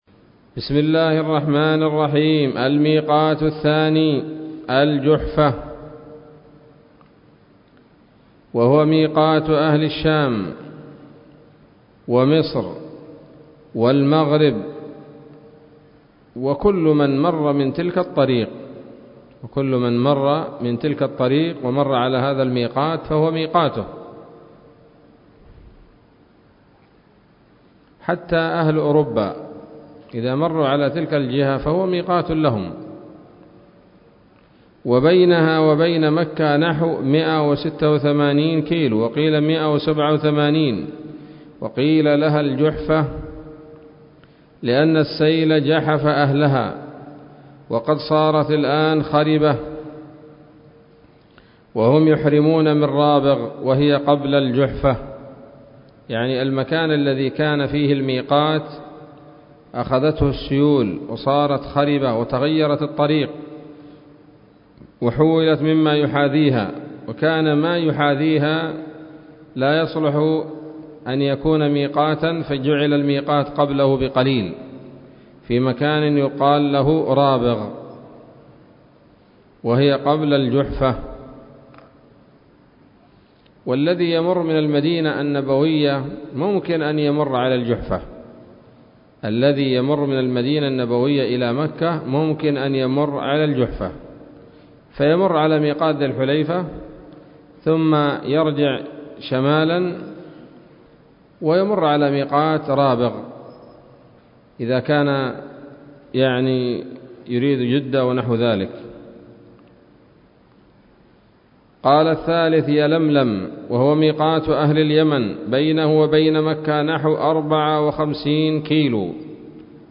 الدرس السابع من شرح القول الأنيق في حج بيت الله العتيق